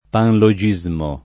[ panlo J&@ mo ]